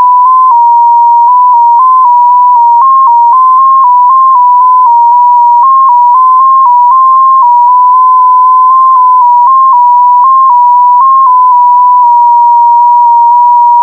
A single carrier of constant amplitude is stepped (between 16 or 32 tone frequencies respectively) in a constant phase manner.
32-FSK
154 Hz
MFSK4.wav